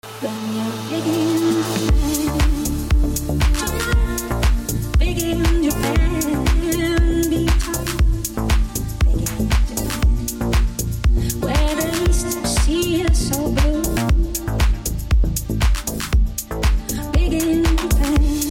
Popular Deep house bass